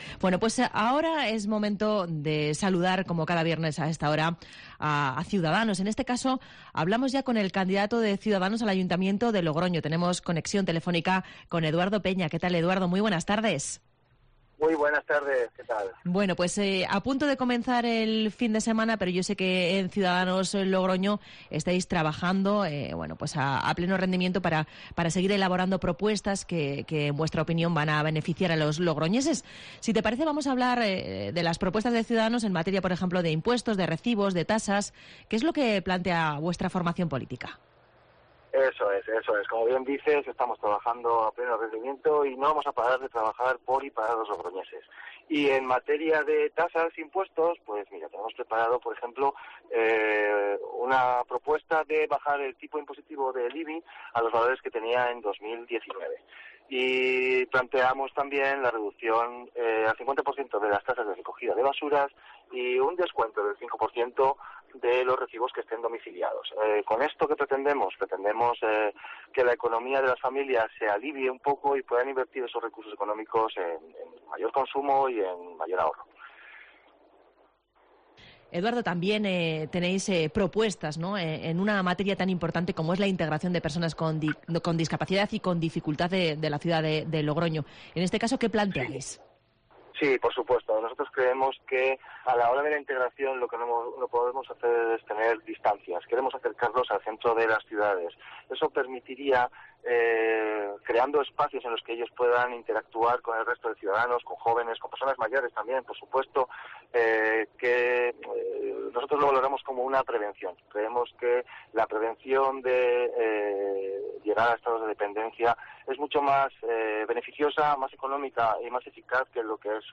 en los micrófonos de COPE